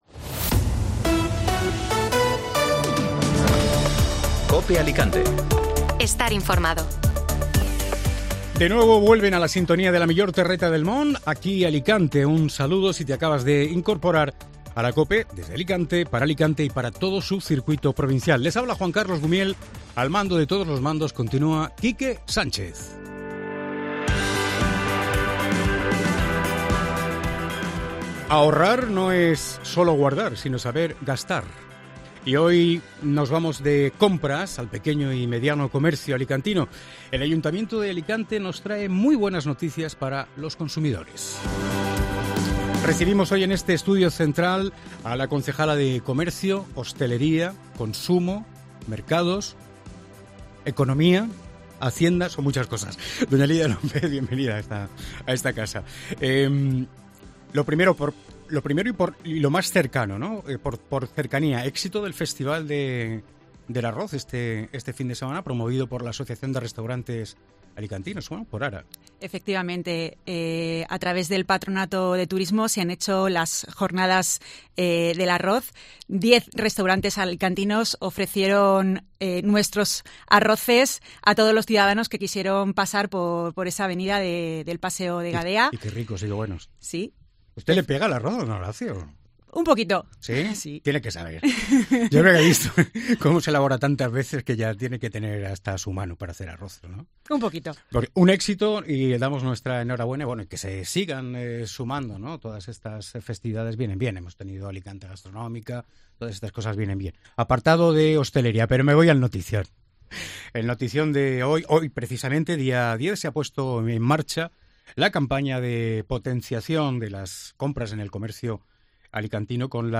Lidia López, edil de Comercio en el Ayuntamiento de Alicante, habla en COPE de los 100 años del Mercado Central y de los bonos comercio